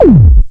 Bassdrum-04.wav